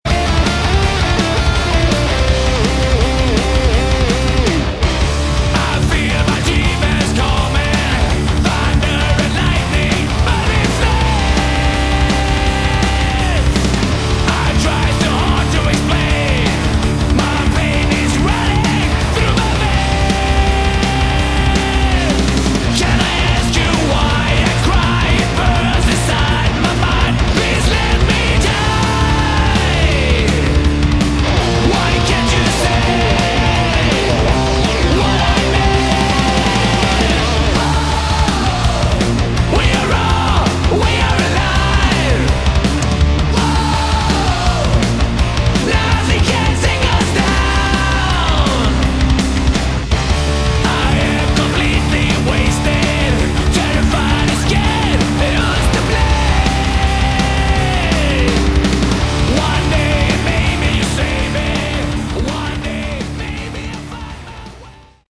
Excellent sleazy hard rock!
This is good old-school dirty sleazy hard rock.